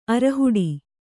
♪ arahuḍi